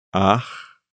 Som: [x]
Maneira de articulação: fricativa (os articuladores se aproximam para que se produza uma fricção na passagem do ar).
Lugar de articulação: velar (parte posterior da língua e véu palatino).
Estado da glote: desvozeado (não há vibração das pregas vocais).
fricveldesv.mp3